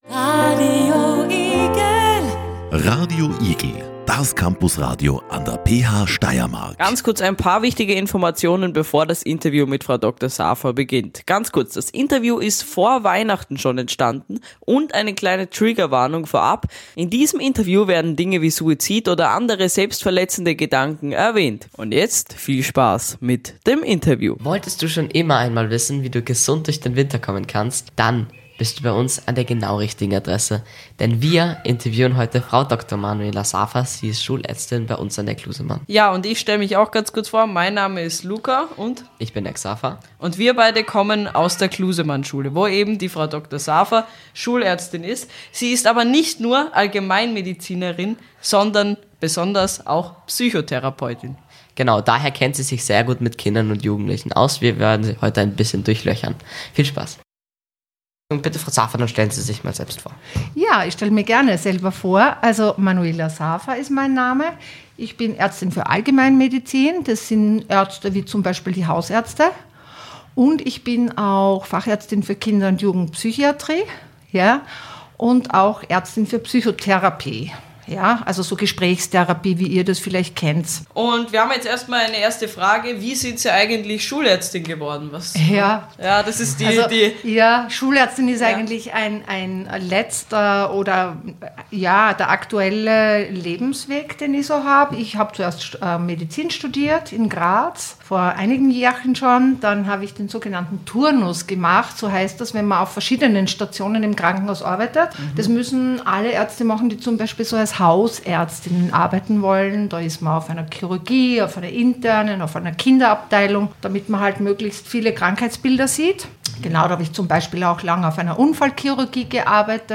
Im Gespräch mit einer Schulärztin